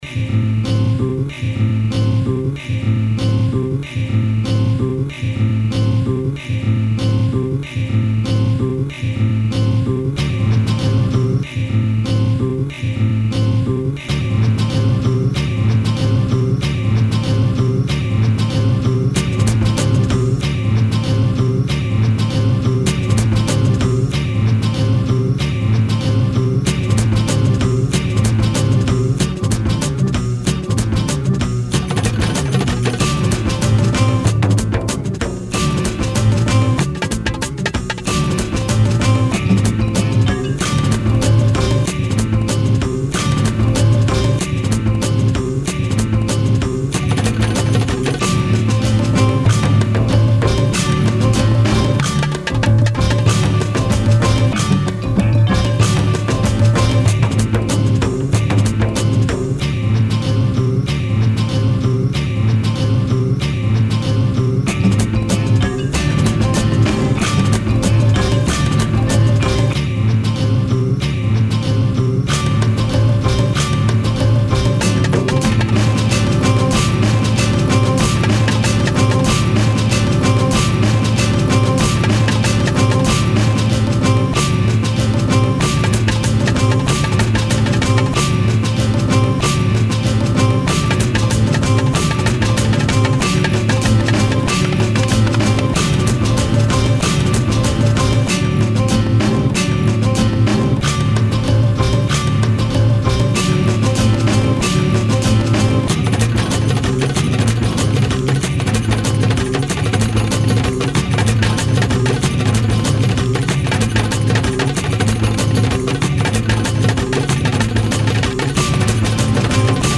One more loop salad coming up.